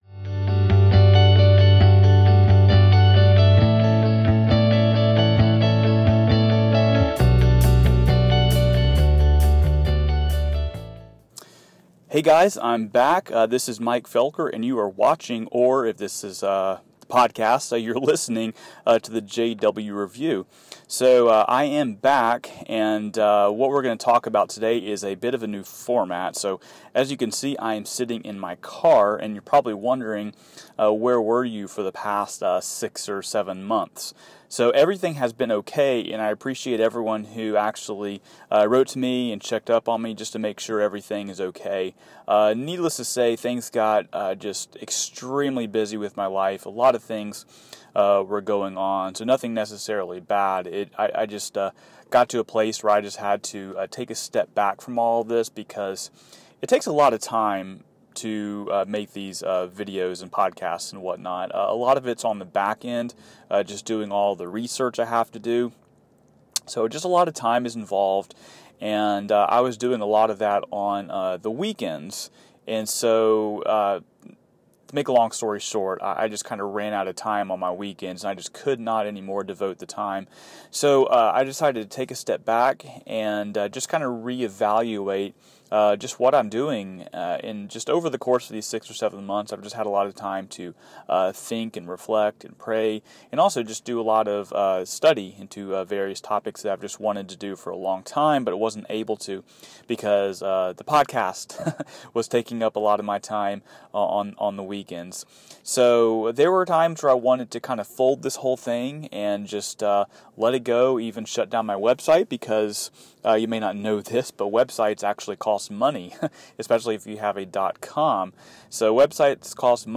In this podcast, I explain my reasons for the break, the new format for the Podcast, as well as why I do this JW-type ministry at all. This new format will definitely be a work in progress, especially getting the audio quality right. But there is only so much you can do mobile.